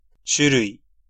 Prononciation
Prononciation France: IPA: /ʒɑ̃ʁ/